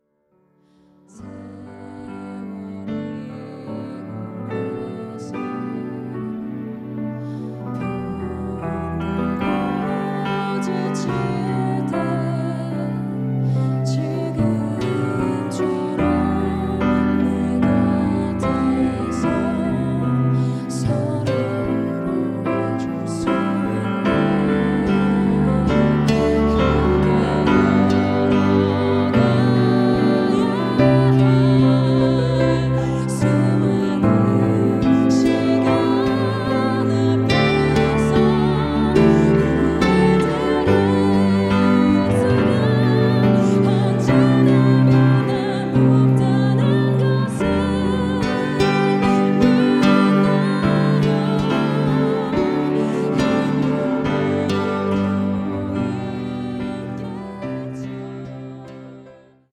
음정 -1키 4:17
장르 가요 구분 Voice Cut